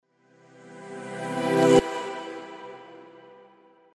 Воздушный финал